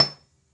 描述：杵和臼打击乐击中
标签： 命中 打击乐 杵和迫击炮
声道立体声